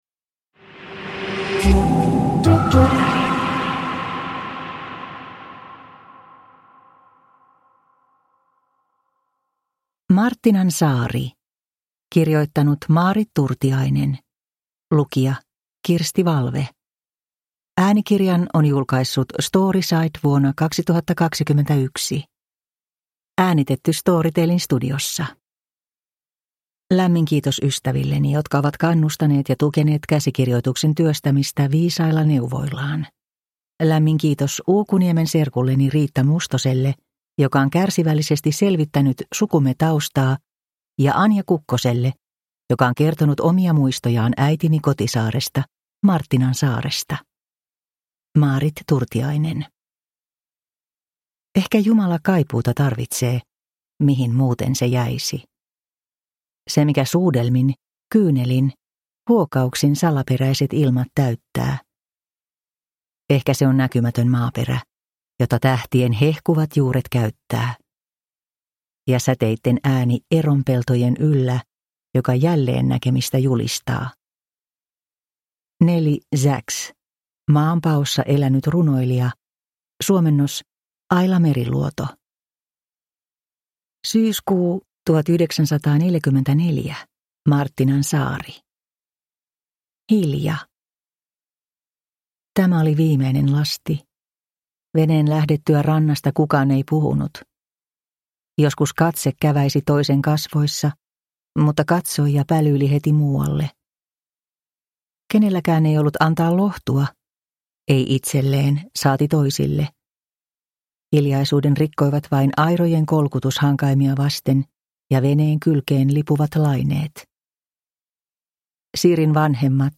Marttinansaari – Ljudbok – Laddas ner